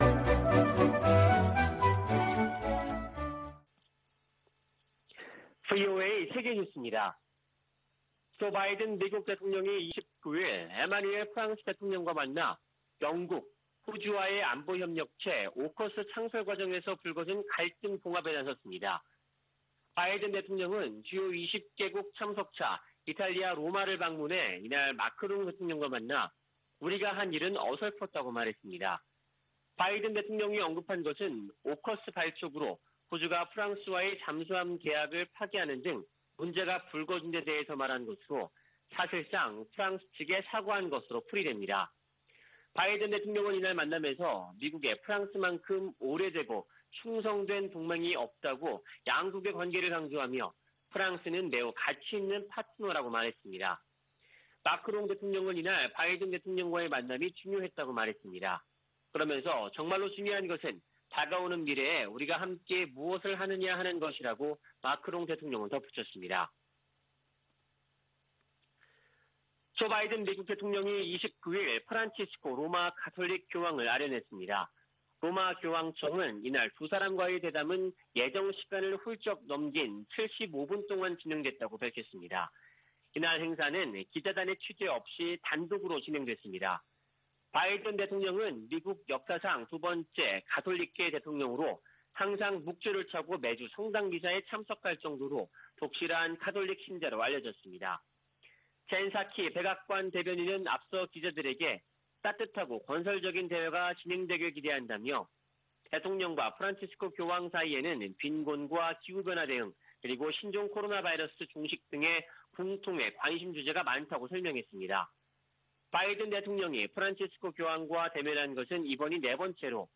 VOA 한국어 아침 뉴스 프로그램 '워싱턴 뉴스 광장' 2021년 10월 30일 방송입니다. 북한 신의주와 중국 단둥간 철도 운행이 다음달 재개될 가능성이 있다고 한국 국가정보원이 밝혔습니다. 유럽연합이 17년 연속 유엔총회 제3위원회에 북한 인권 상황을 규탄하는 결의안을 제출했습니다. 유엔총회 제1위원회에서 북한 핵과 탄도미사일 관련 내용 포함 결의안 3건이 채택됐습니다.